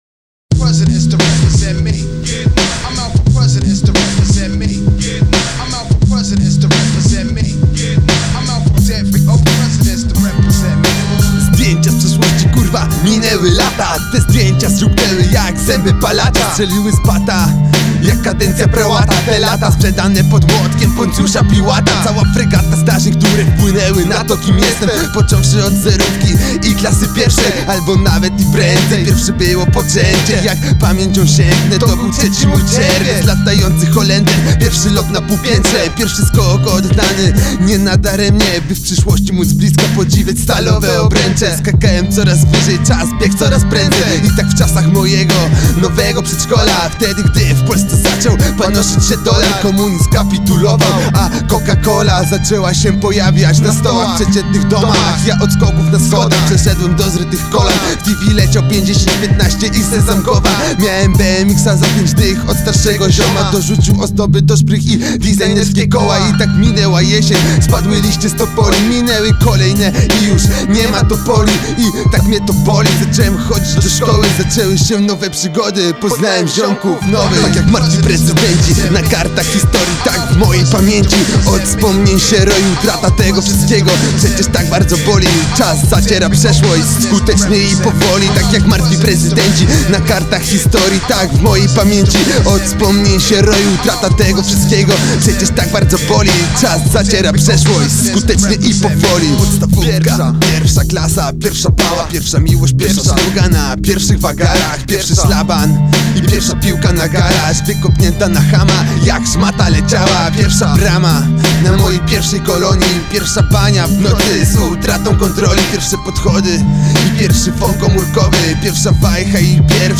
hip-hop, rap